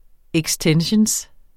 Udtale [ εgsˈtεnɕəns ]